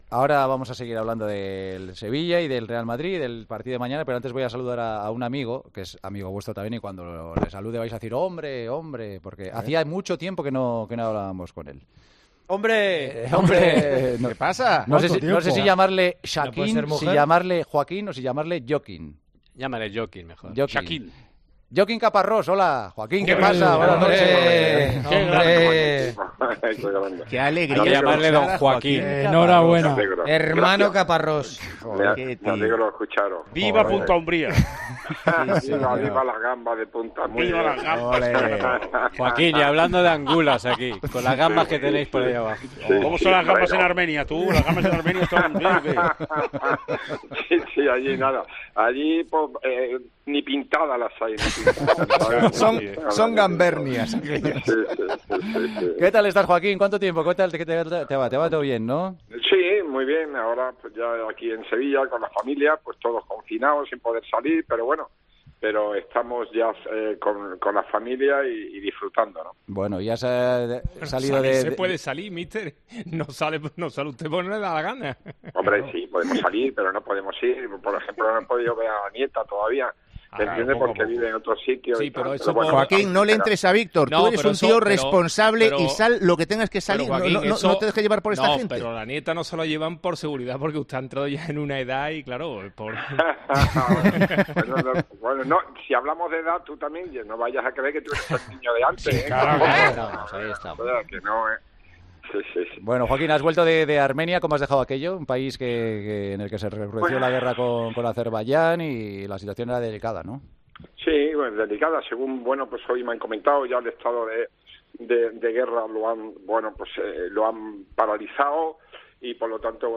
AUDIO: Hablamos con el seleccionador de Armenia y ex entrenador del Sevilla, en la previa del partido de los andaluces ante el Real Madrid.